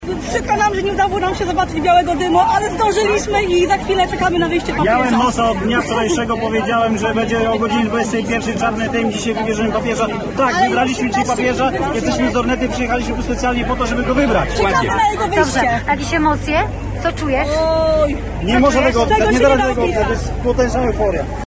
Relacja prosto z Watykanu